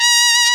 Index of /m8-backup/M8/Samples/FAIRLIGHT CMI IIX/BRASS2